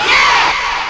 Tm8_Chant66.wav